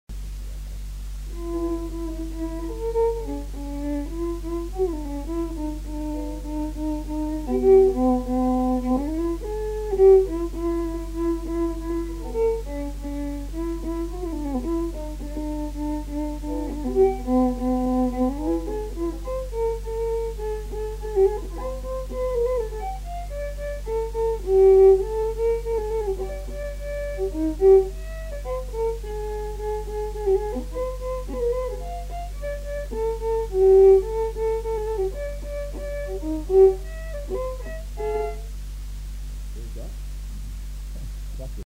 Rondeau de Noël